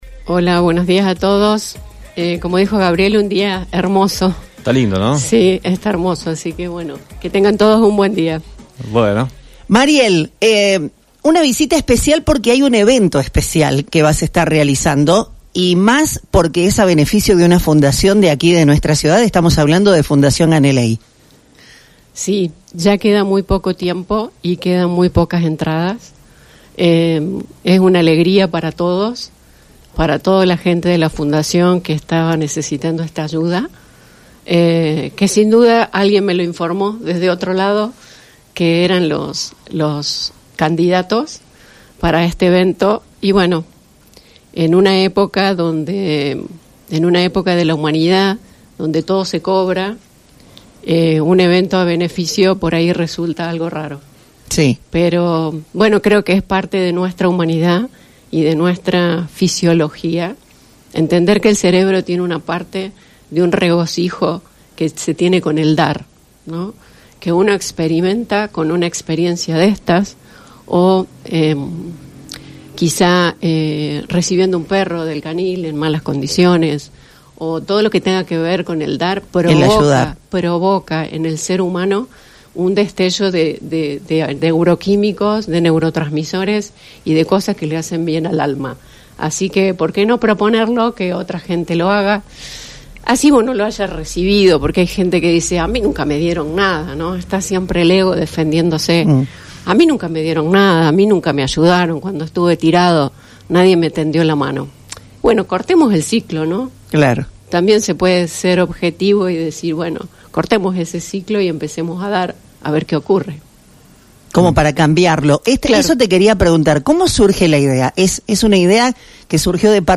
Entre mundos: Una charla íntima con la médium